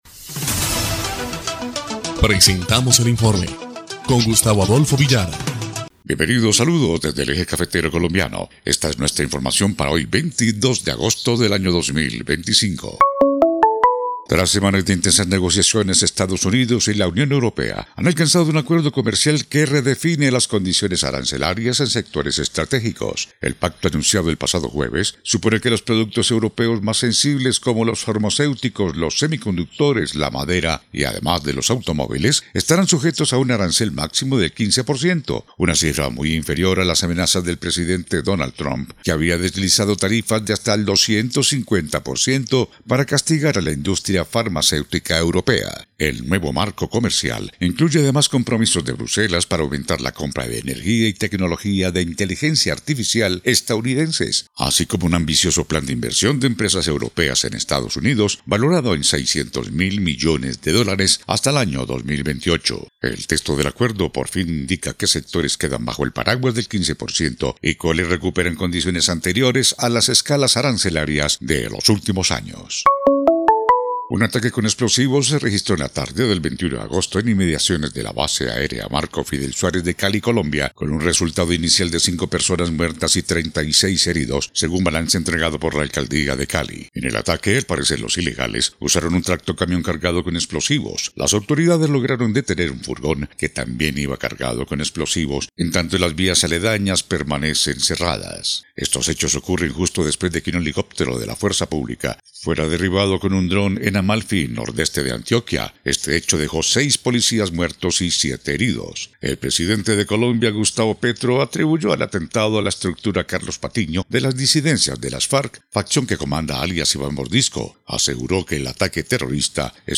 EL INFORME 1° Clip de Noticias del 22 de agosto de 2025